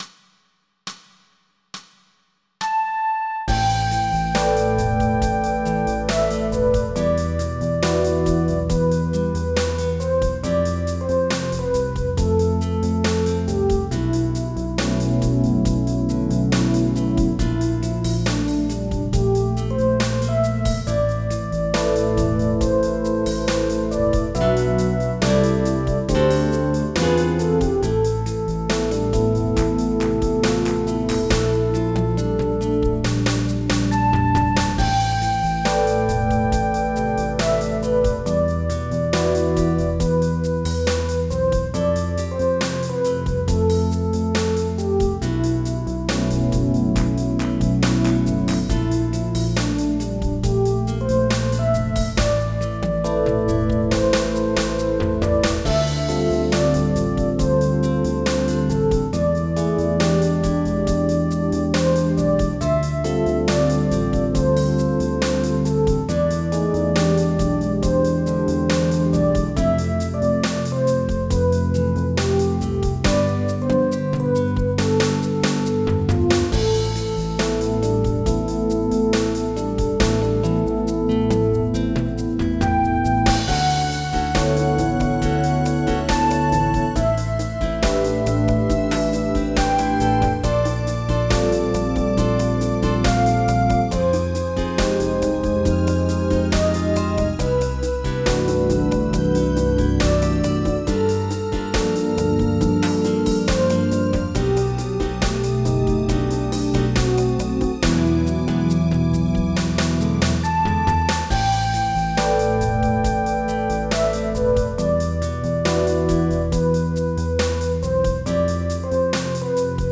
Jazz
General MIDI